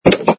sfx_put_down_bottle05.wav